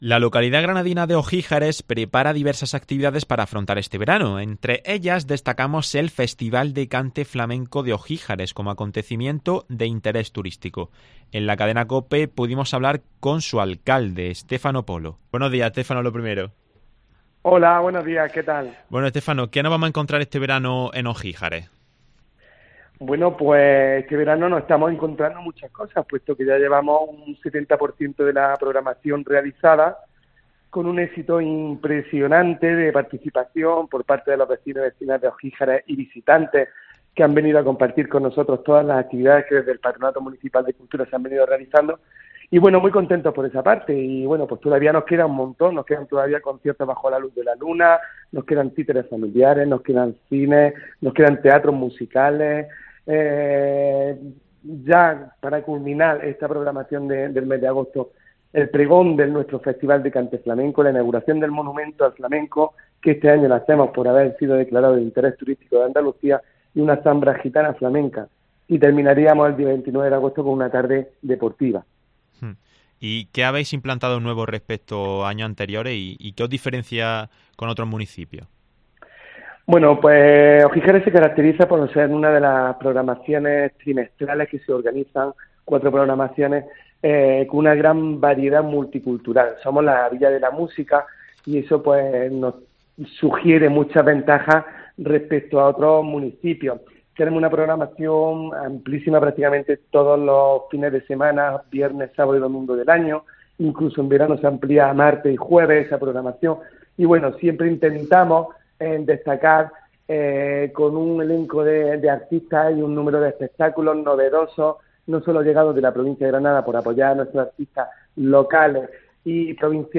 En la cadena COPE, pudimos hablar con Estéfano Polo, alcalde del municipio.